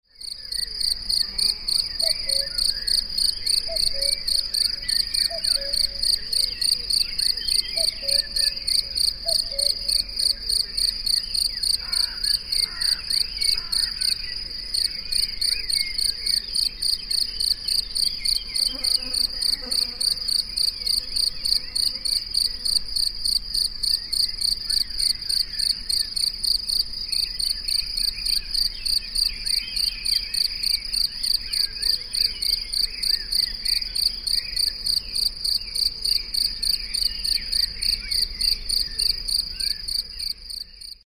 Bis zu 100m weit hörbar… der markante Gesang der Feldgrille ist von April bis Juli unüberhörbar.
So tönt die Feldgrille
feldgrille-natur-konkret.mp3